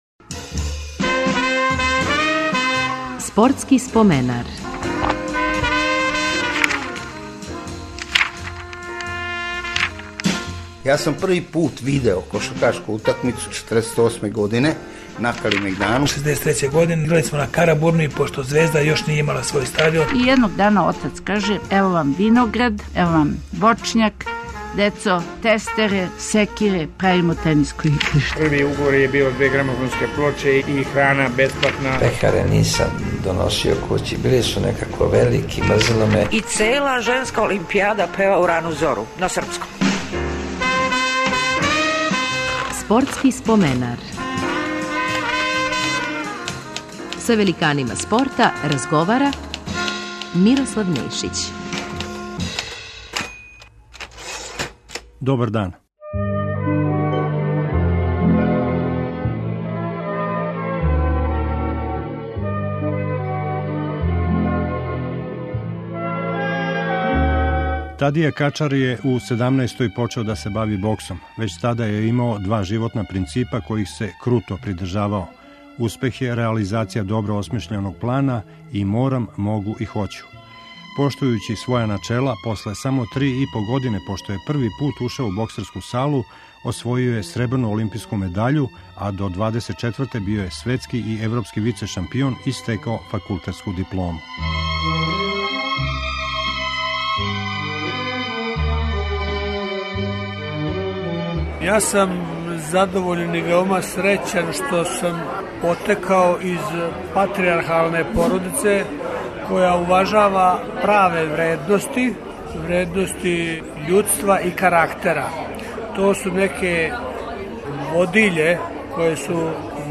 Гост емисије је боксер Тадија Качар. Као ретко који спортиста имао је брз успон.